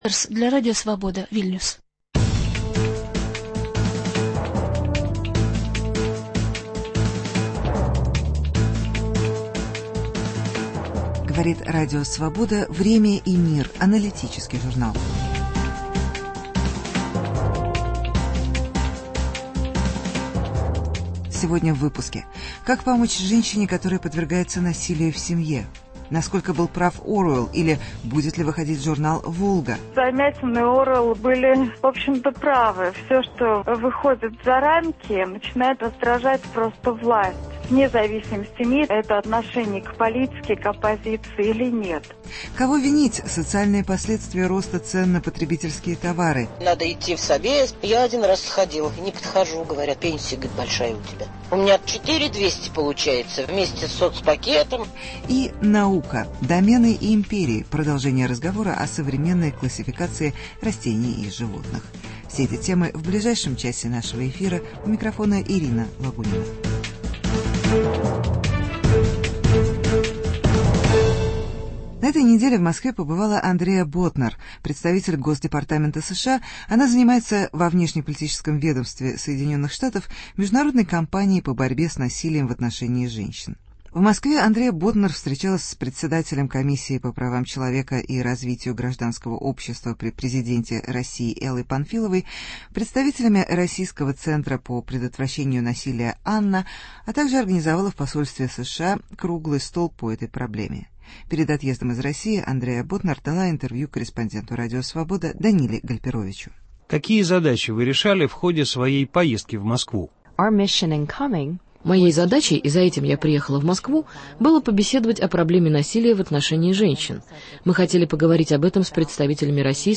Насилие в семье: интервью с заместителем госсекретаря США.